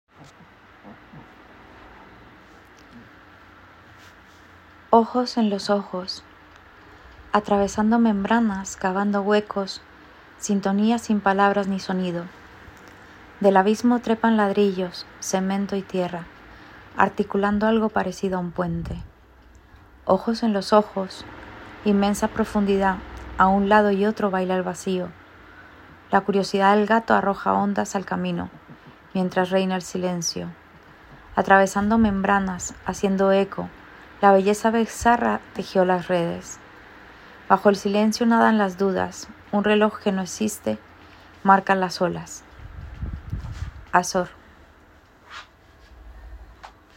audioEscuchar poema en la voz de su autora